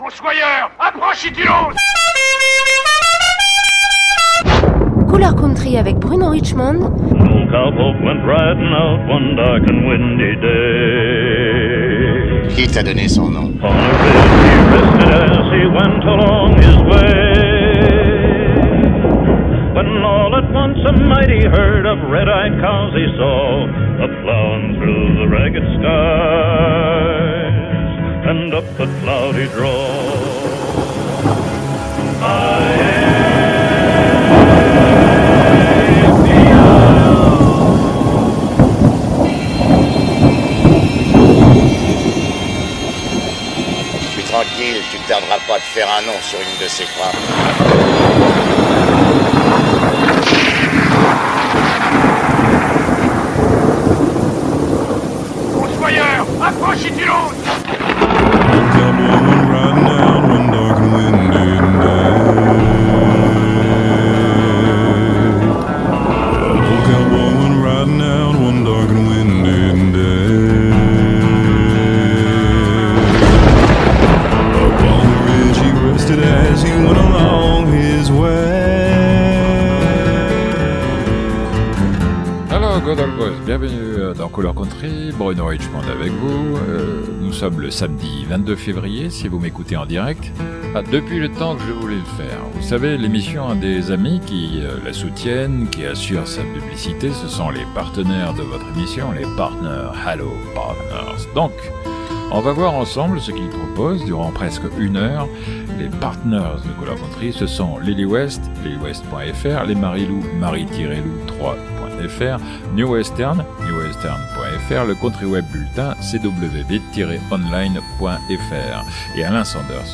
Le direct